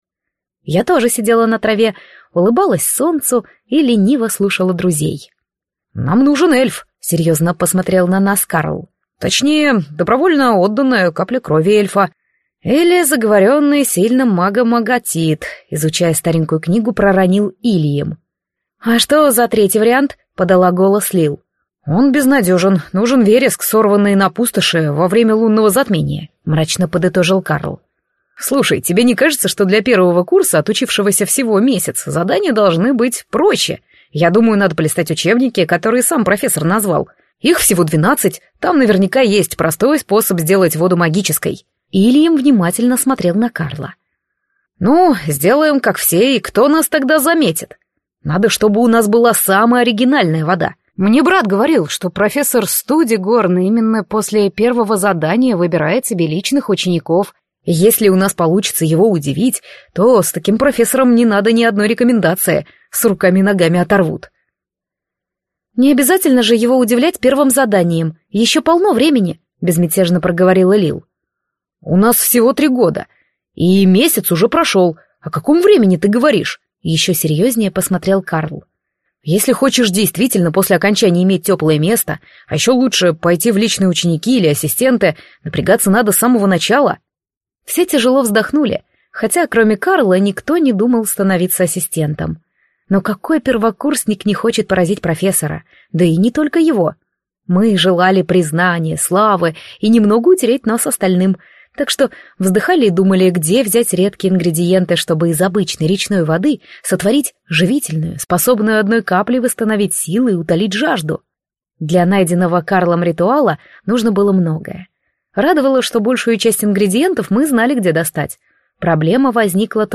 Аудиокнига Зелье первокурсников | Библиотека аудиокниг
Прослушать и бесплатно скачать фрагмент аудиокниги